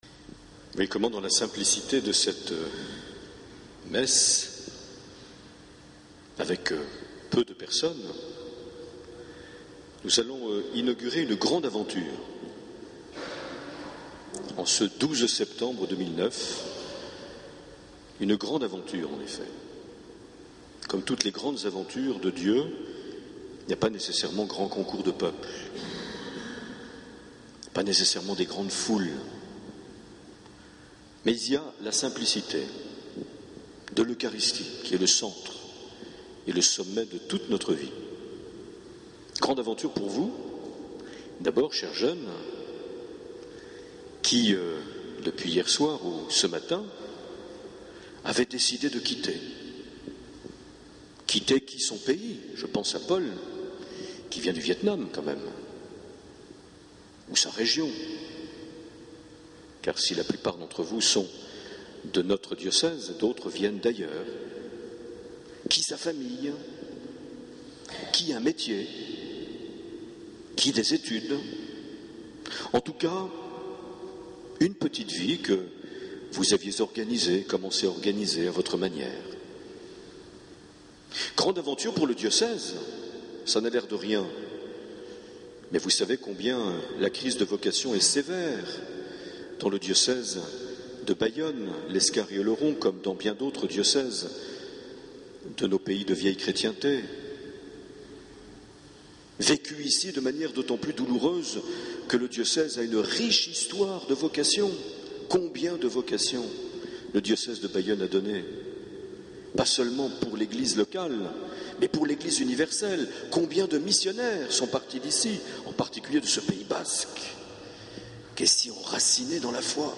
12 septembre 2009 - Bayonne église Saint Amand - Lancement de l’année de propédeutique
Une émission présentée par Monseigneur Marc Aillet